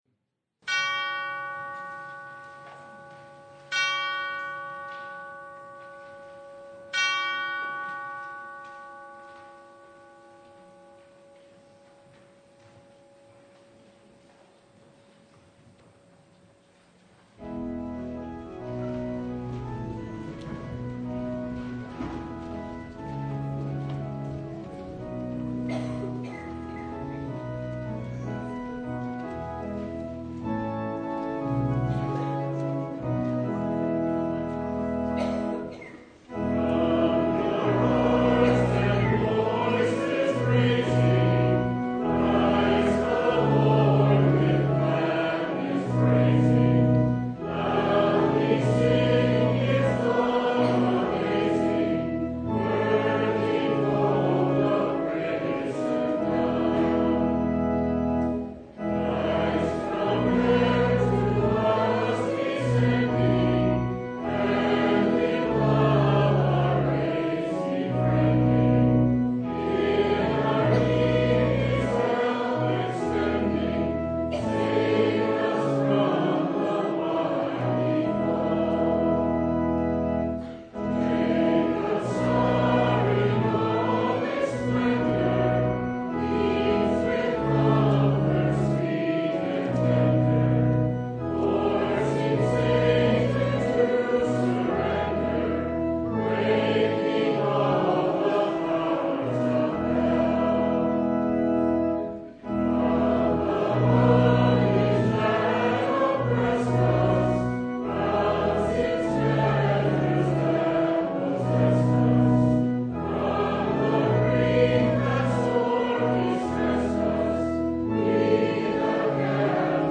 Christmas Eve Vespers (2024)
Passage: Luke 2:8-16 Service Type: Christmas Eve Vespers Download Files Bulletin Topics: Full Service